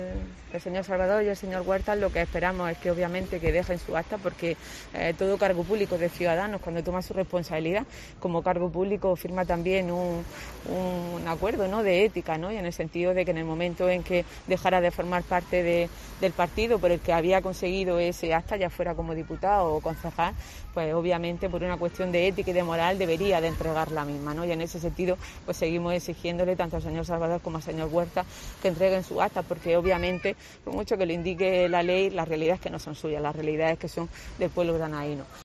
"Seguimos exigiendo al señor Salvador y al señor Huertas que entreguen sus actas. Por mucho que le indique la ley, la realidad es que no son suyas sino del pueblo granadino", ha manifestado Bosquet a preguntas de los medios en Balerma (Almería) donde ha asegurado que dicha acción obedece a una "cuestión de ética y moral".